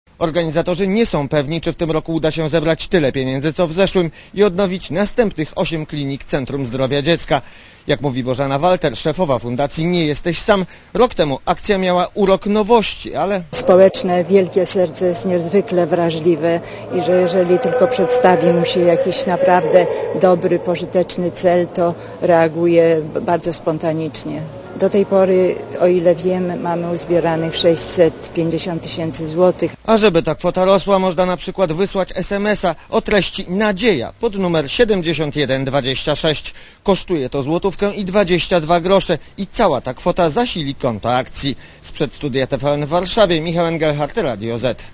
W studiu TVN był reporter Radia ZET